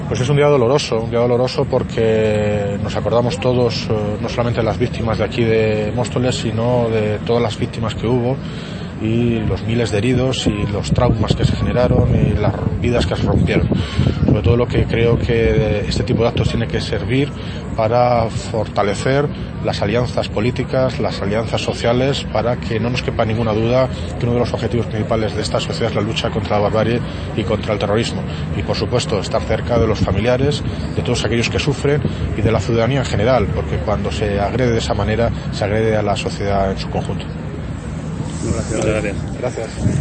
Audio - David Lucas (Alcalde de Móstoles) Sobre 11 de marzo